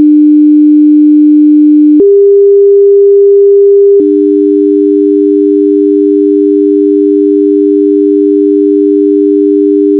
Sound sample A contains a 400 Hz simple tone.